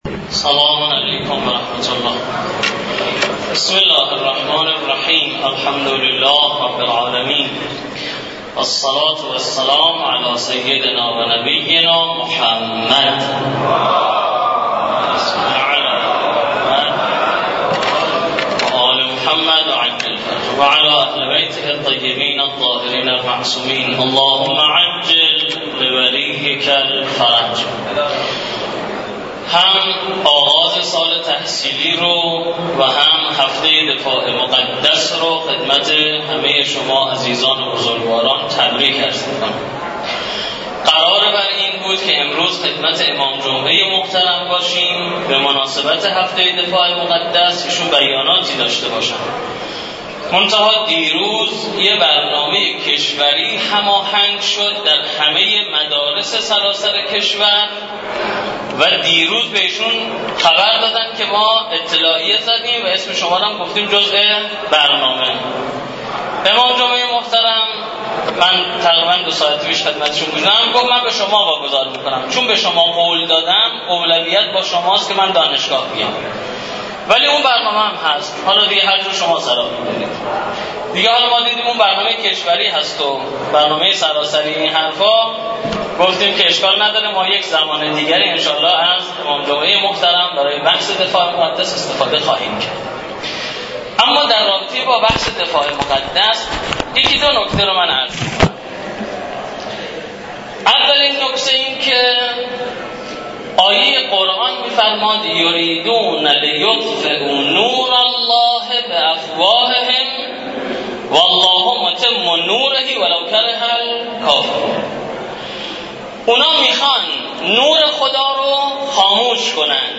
سخنرانی مسئول نهاد رهبری به مناسبت آغاز سال تحصیلی و آغاز هفته دفاع مقدس